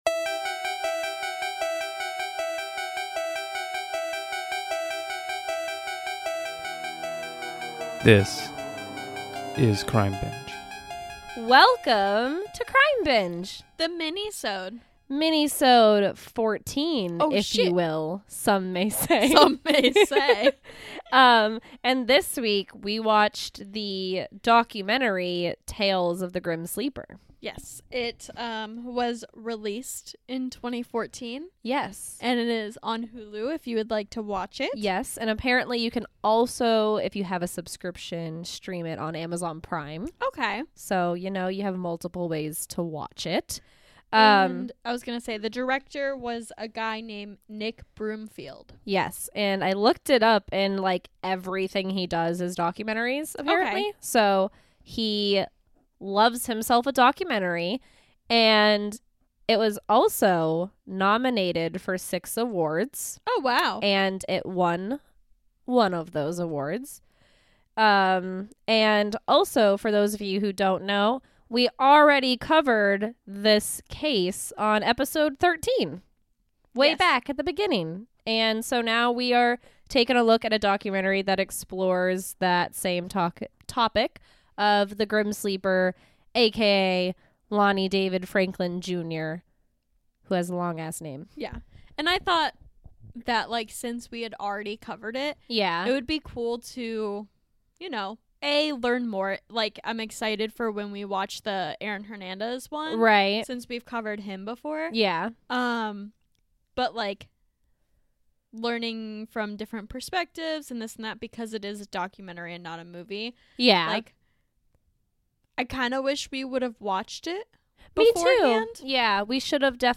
This week on Crime Binge the girls watch and discuss Tales Of The Grim Sleeper, a documentary that was directed by Nick Broomfield.